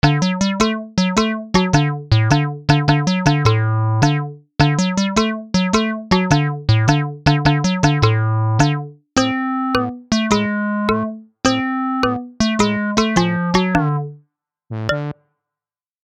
funny-and-comical-melody-glide-synth-bass-and-trumpet-21398.mp3